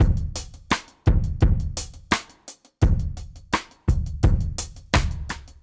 Index of /musicradar/dub-drums-samples/85bpm
Db_DrumsB_Dry_85-03.wav